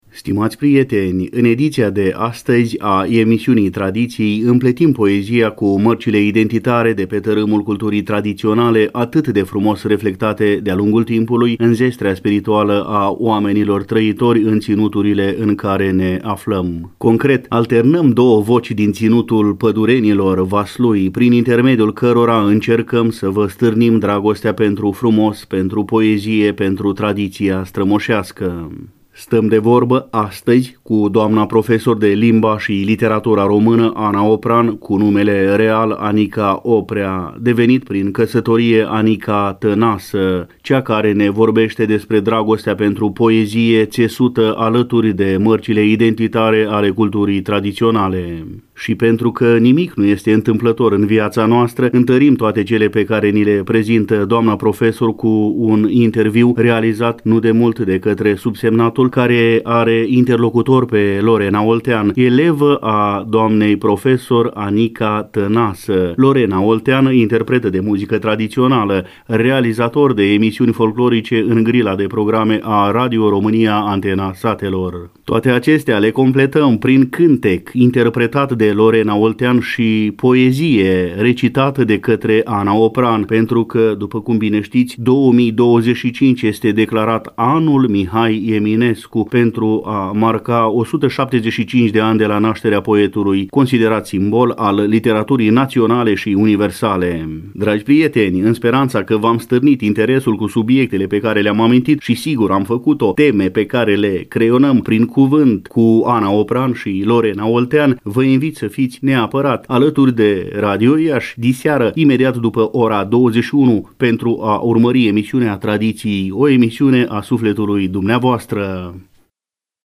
Concret, alternăm două voci din Ținutul Pădurenilor, județul Vaslui, prin intermediul cărora încercăm să vă stârnim dragostea pentru frumos, pentru poezie, pentru tradiția strămoșească.